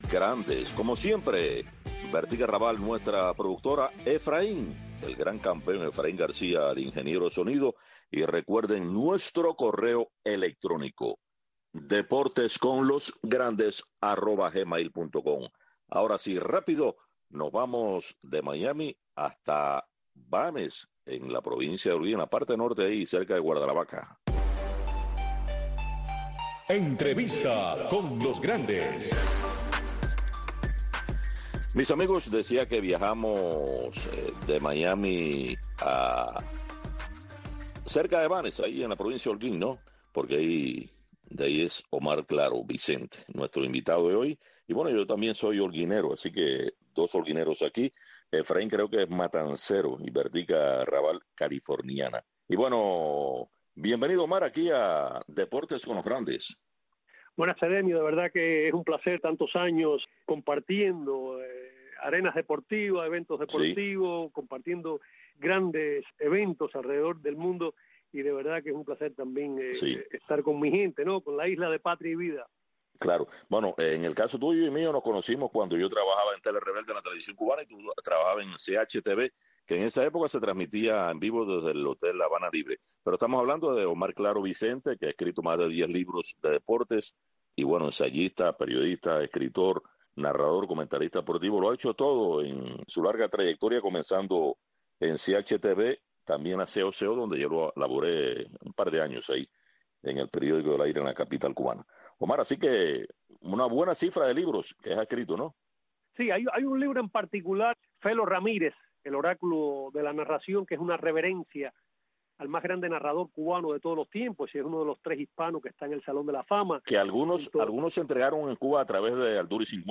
Deportes con los grandes. Un programa de Radio Martí, especializado en entrevistas, comentarios, análisis de los grandes del deporte.